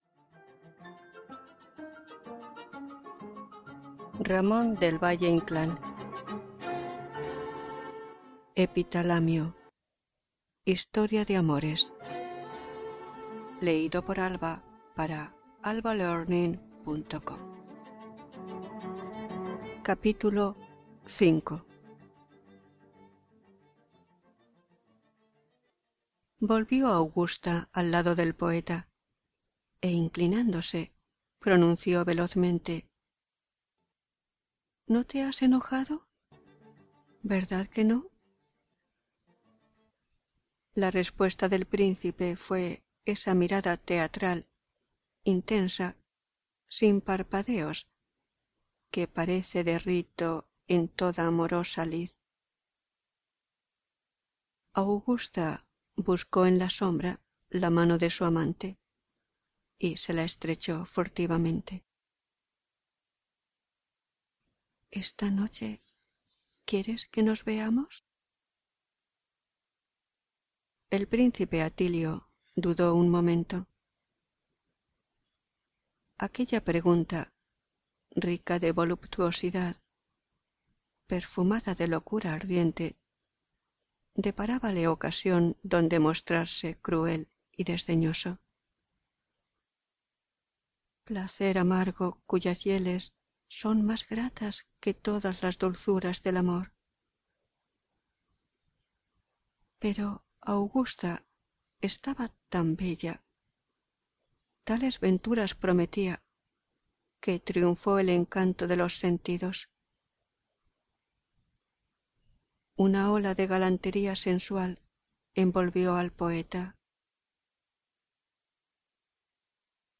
AlbaLearning - Audiolibros y Libros - Learn Spanish
Música: Dvorak - Piano Trio No. 2 in G minor, Op. 26 (B.56) - 3: Scherzo: Presto